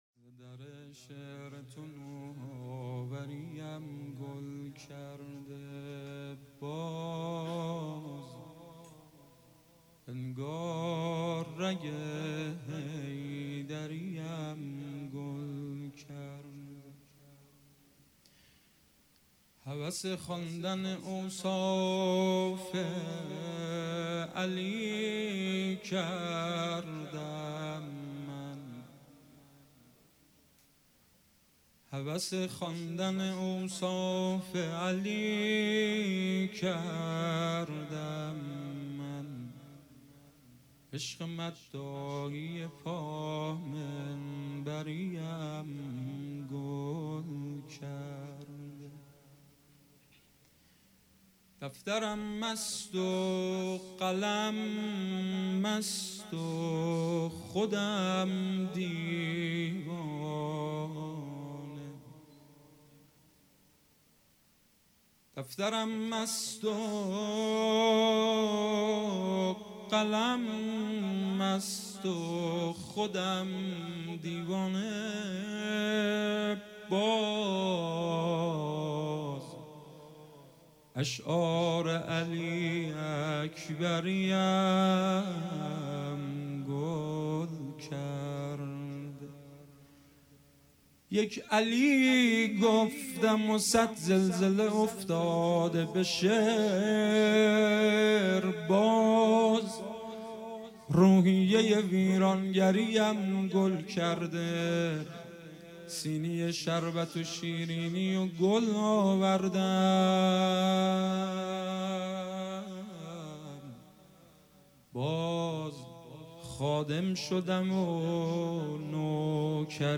جشن ولادت حضرت علی اکبر علیه السلام